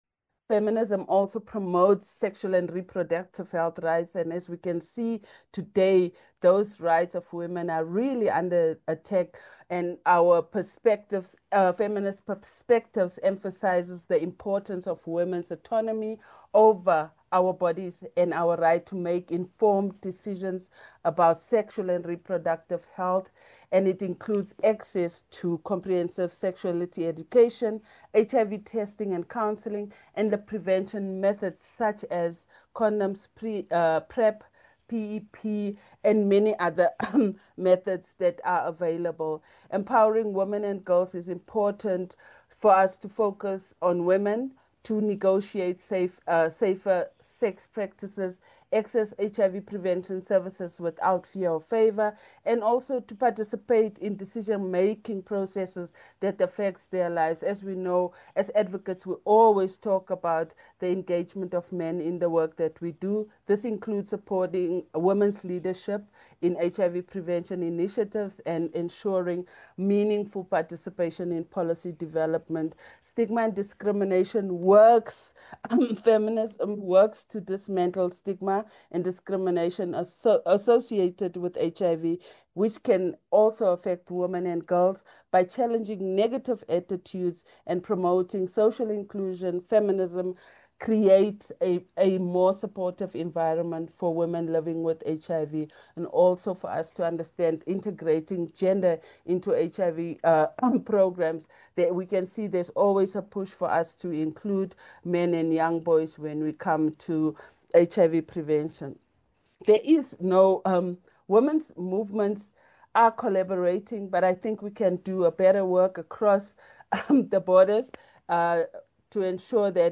HIV-Prevention-Webinar-Four.mp3